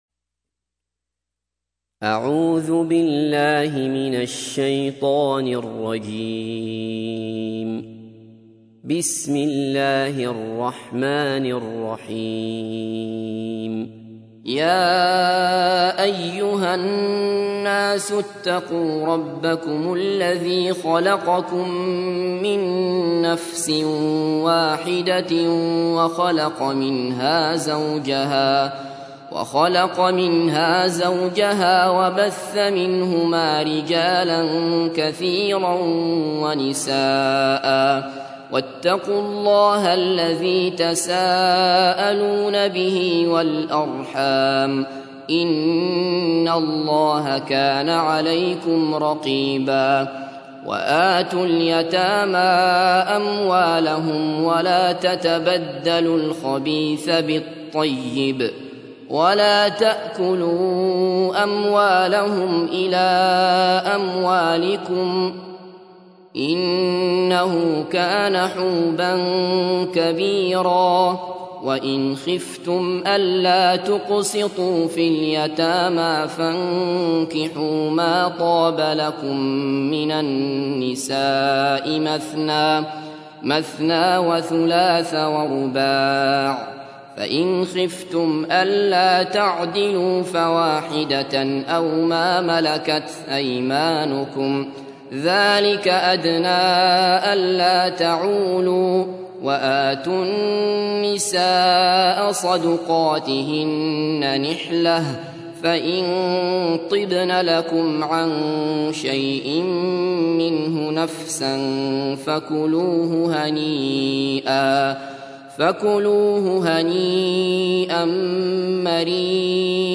تحميل : 4. سورة النساء / القارئ عبد الله بصفر / القرآن الكريم / موقع يا حسين